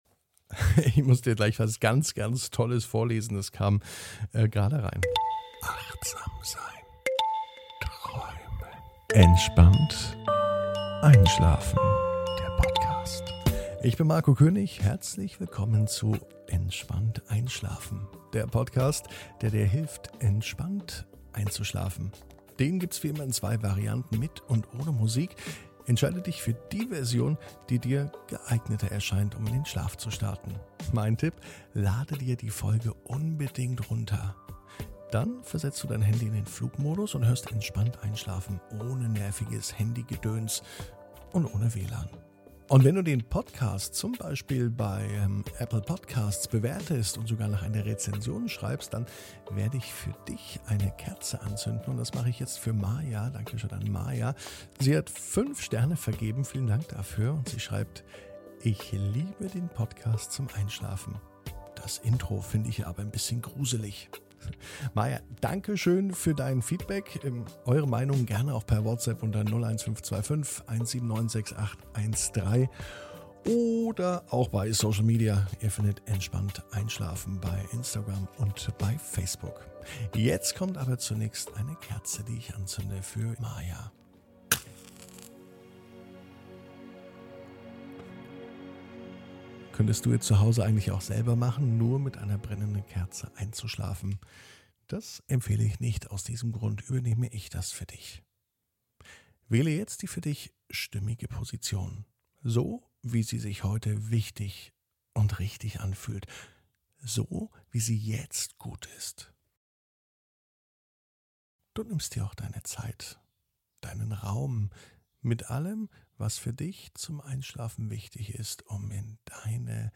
(ohne Musik) Entspannt einschlafen am Dienstag, 22.06.21 ~ Entspannt einschlafen - Meditation & Achtsamkeit für die Nacht Podcast